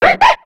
Cri de Zorua dans Pokémon X et Y.